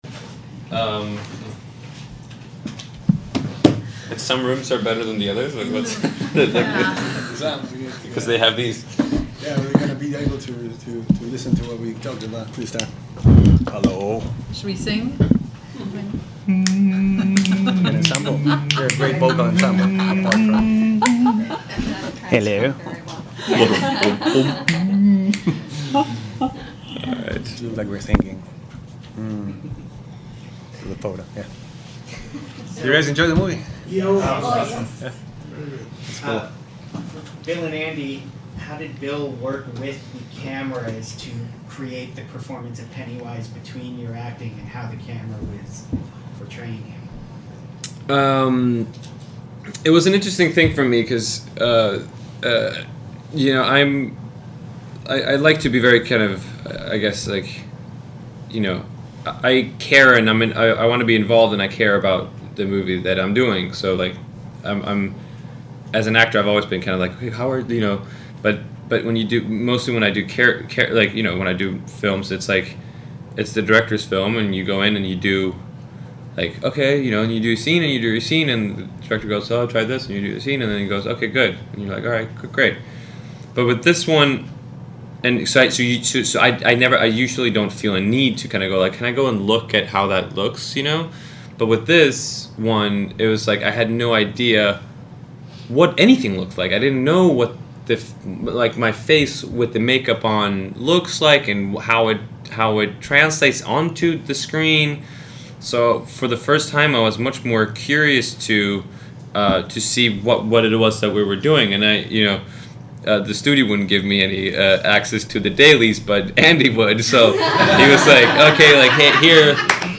dom_press_conf_audio_billskarsgard_andymuschietti_barbaramuschietti.mp3